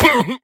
Minecraft Version Minecraft Version snapshot Latest Release | Latest Snapshot snapshot / assets / minecraft / sounds / mob / vindication_illager / death2.ogg Compare With Compare With Latest Release | Latest Snapshot
death2.ogg